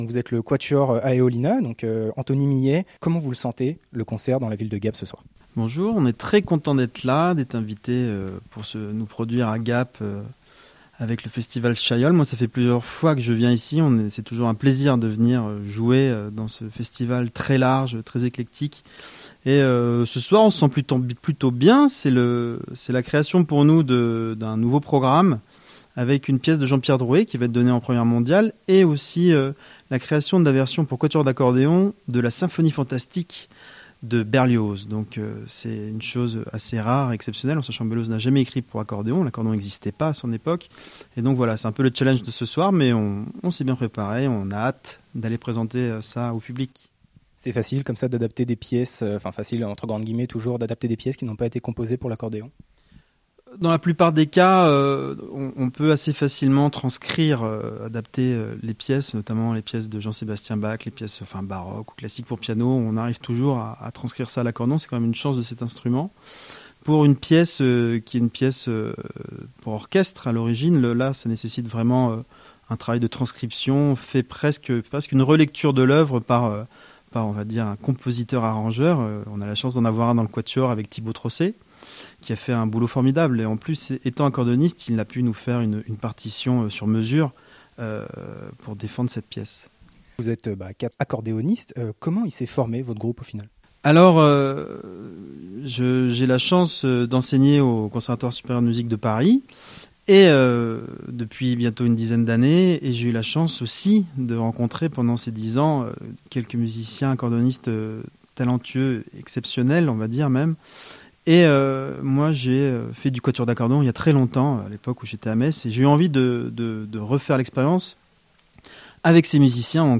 Composé de quatre accordéonistes de talent, cette formation atypique nous à fait découvrir ou redécouvrir des pièces de musiques classiques et plus contemporaines, adaptées et réécrites pour accordéon.